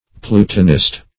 Search Result for " plutonist" : The Collaborative International Dictionary of English v.0.48: Plutonist \Plu"to*nist\, n. [Cf. F. plutoniste.] One who adopts the geological theory of igneous fusion; a Plutonian.